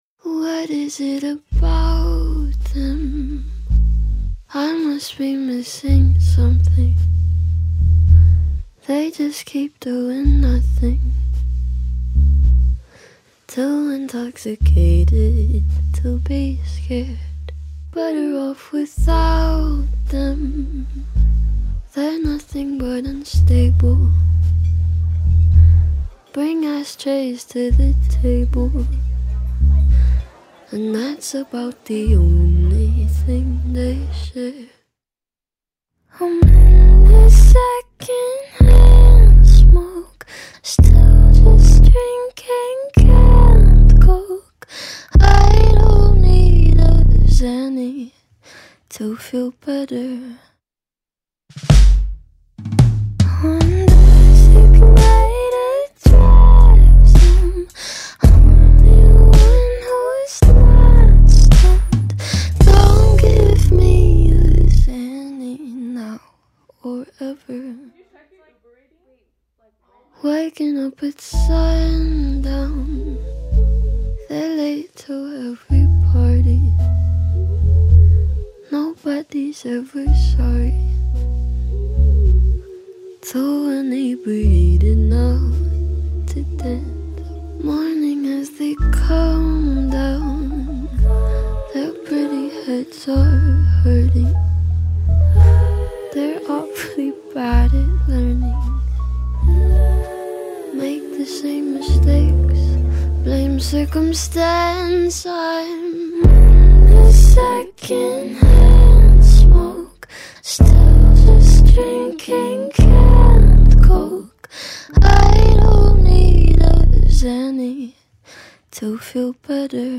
Alternative Pop / Electropop